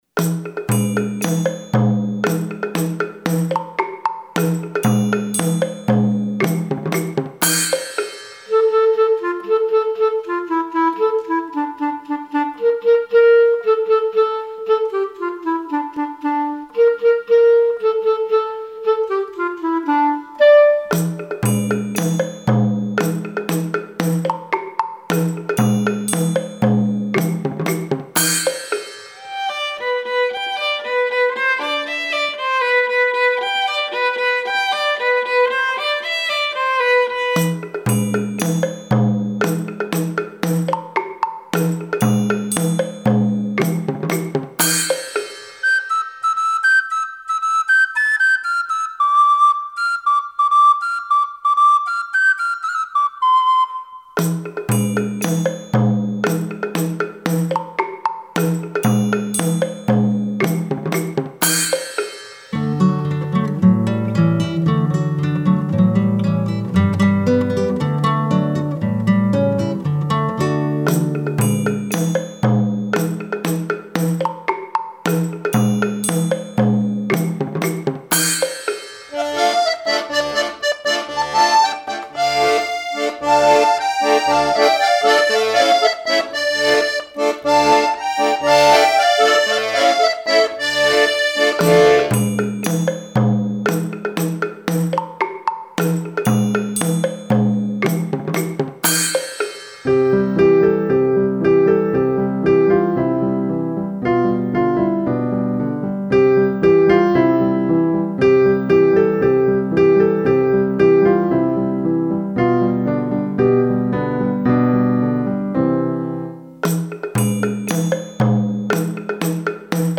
Sobresolamente instrumentales
B -clarinete
C -violín
D -flauta dulce
E -guitarra
F -acordeón
G -piano
H - violonchelo